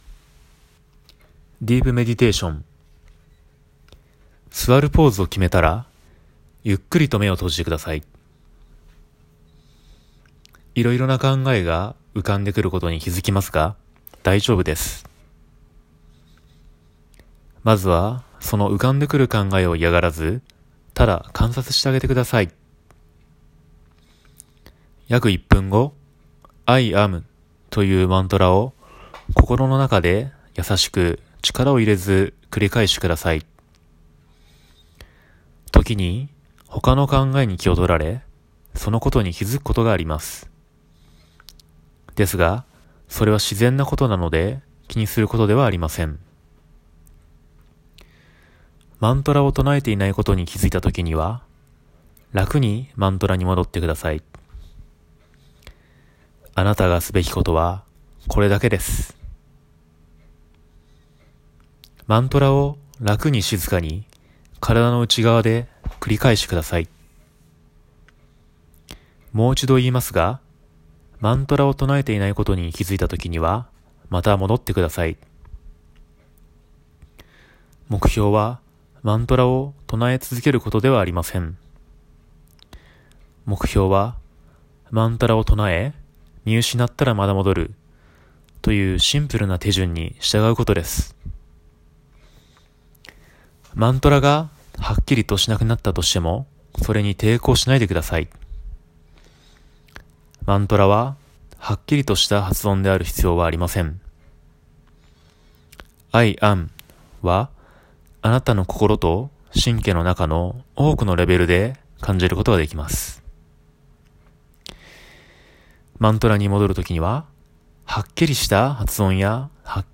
AUDIO VERSION JAPANESE DEEP MEDITATION (ADVANCED YOGA PRACTICES BY YOGANI):
deep-meditation-japanese-ver-1.m4a